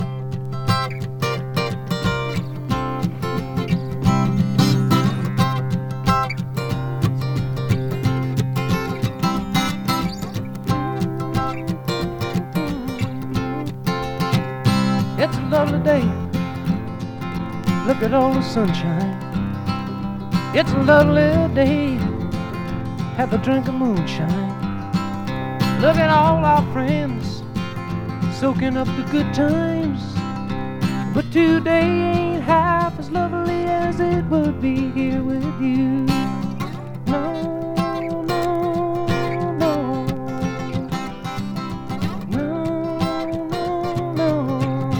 Rock, Pop　USA　12inchレコード　33rpm　Stereo